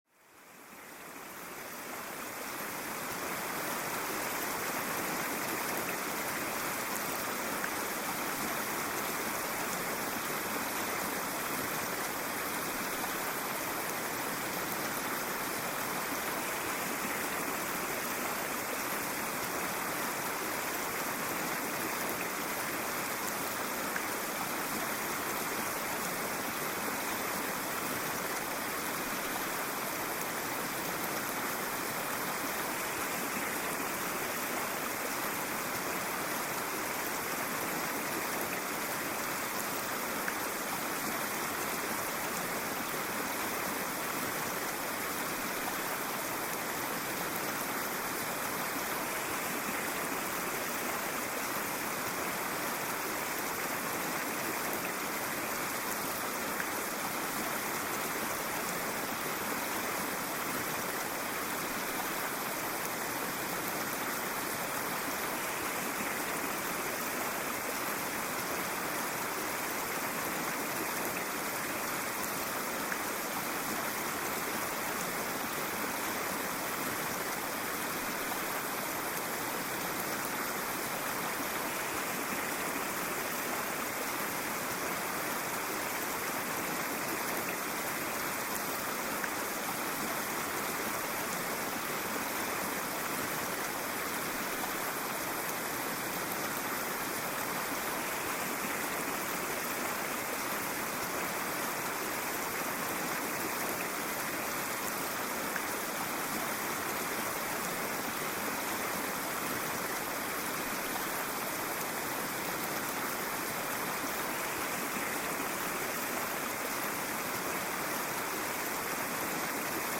Orage nocturne pour un sommeil réparateur naturel